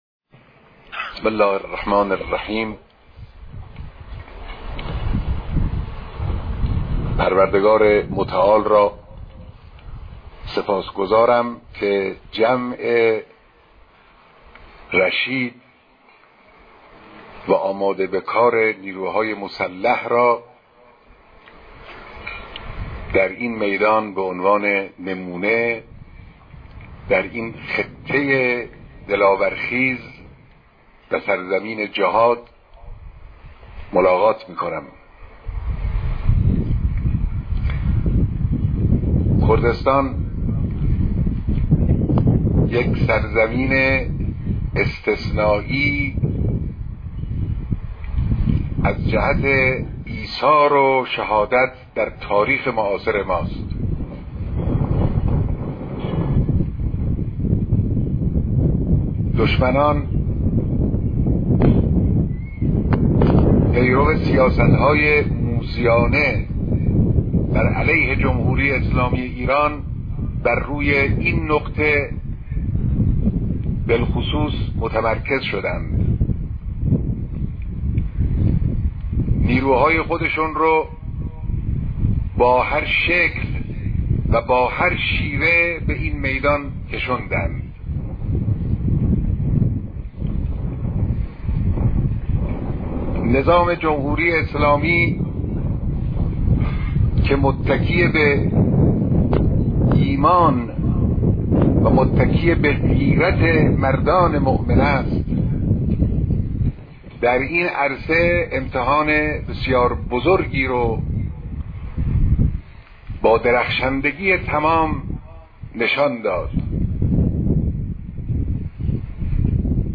مراسم نظامي مشترک نیروهای مسلحِ مستقر در استان کردستان